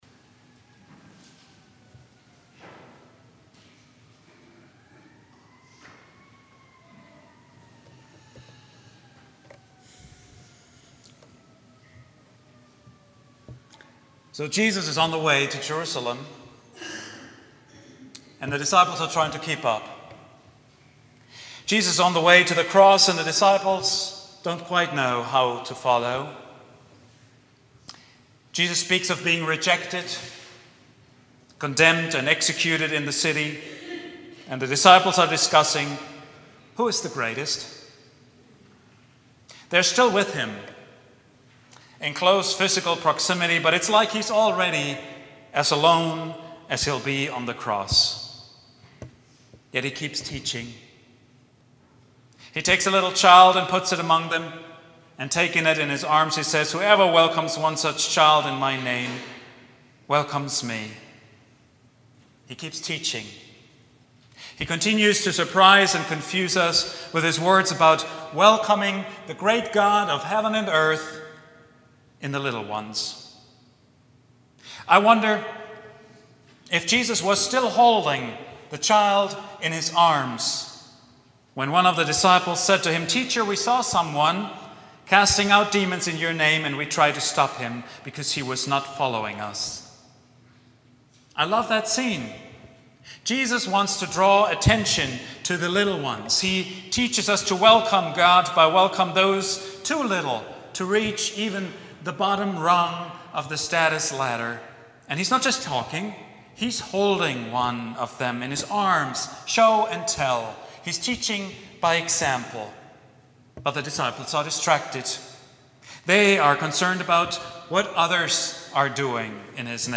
sermon
preached at Vine Street Christian Church on Sunday